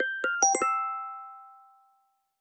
messageReceivedSounds